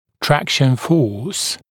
[‘trækʃn fɔːs][‘трэкшн фо:с]сила тяги, вытяжения